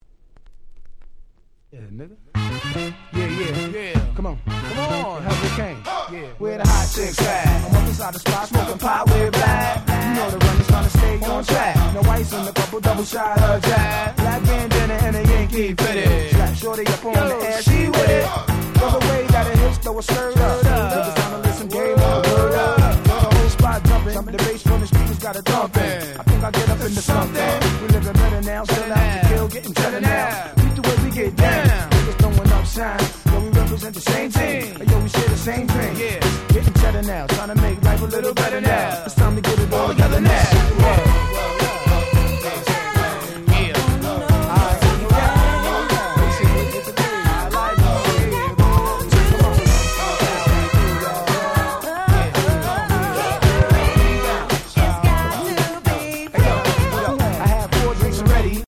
00' Nice Party Rap !!